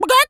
chicken_cluck_scream_01.wav